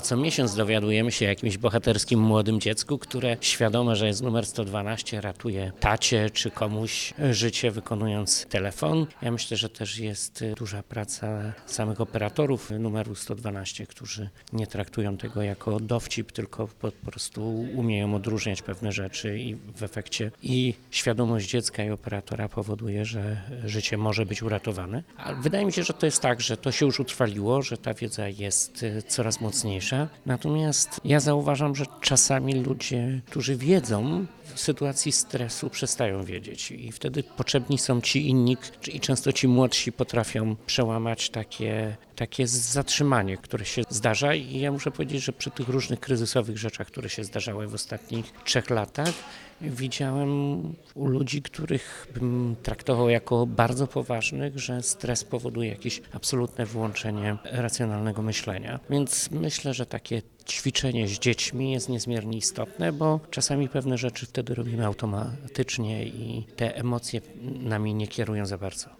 Wojewoda dolnośląski – Jarosław Obremski mówiąc o zakończonym konkursie dla uczniów szkół podstawowych na Dolnym Śląsku, podkreślił, że coraz częściej to dzieci ratują życie swoich najbliższych dzwoniąc pod 112.